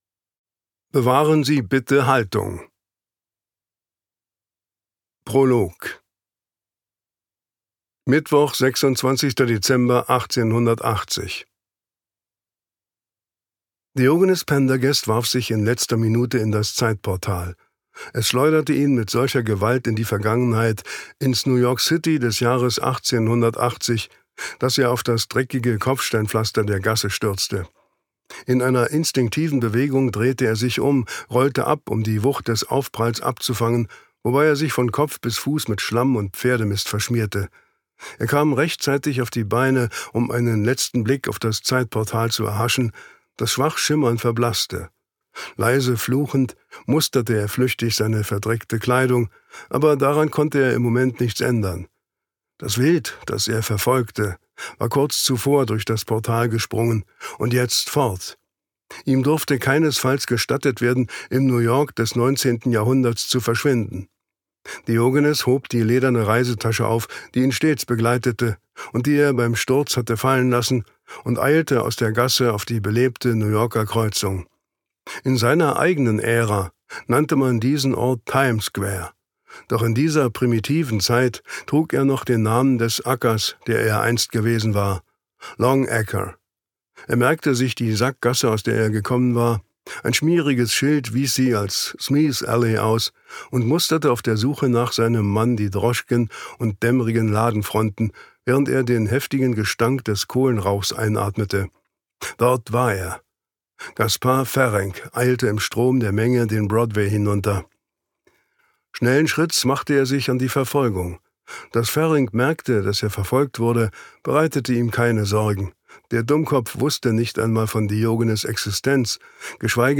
Poison – Schwestern der Vergeltung Ein neuer Fall für Special Agent Pendergast. Thriller | Action-Thriller mit einem spannenden Mix aus Mystery, Drama und historischen Elementen Douglas Preston , Lincoln Child (Autoren) Detlef Bierstedt (Sprecher) Audio Disc 2024 | 2.